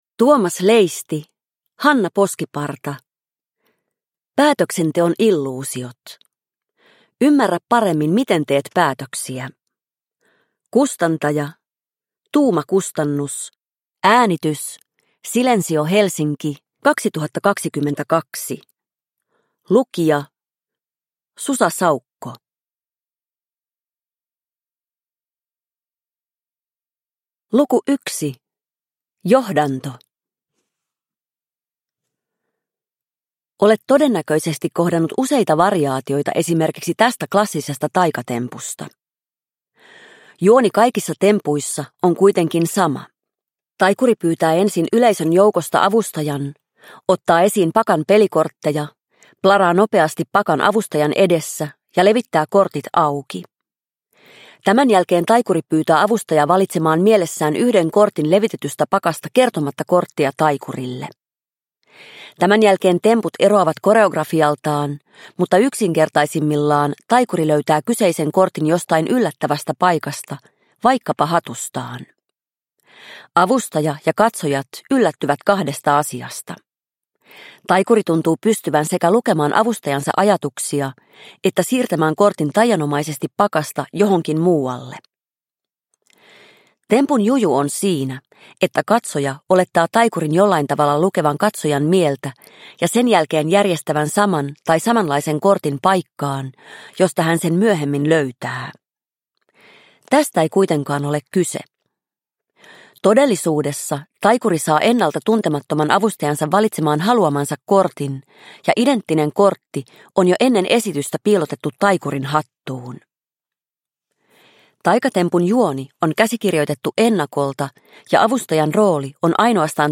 Päätöksenteon illuusiot – Ljudbok – Laddas ner